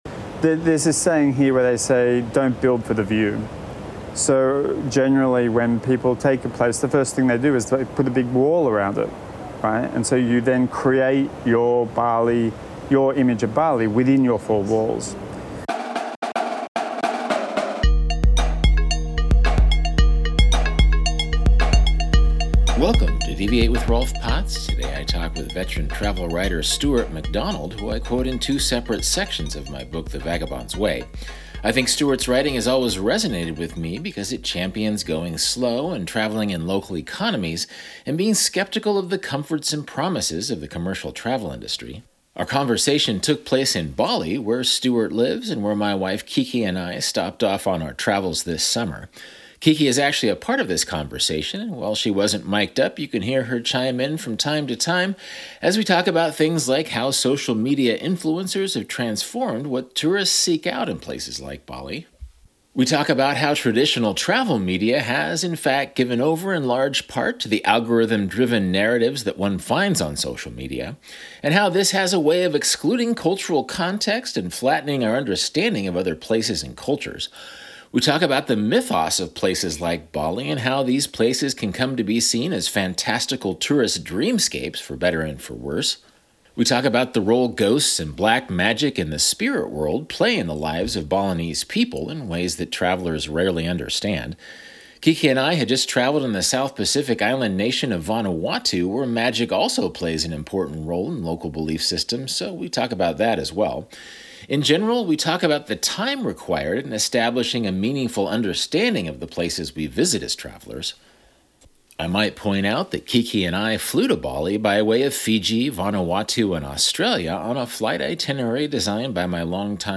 Live from Bali: How tourists, influencers, and nomads transform destinations (for good and for bad)